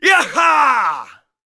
cheers1.wav